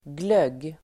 Ladda ner uttalet
Uttal: [glög:]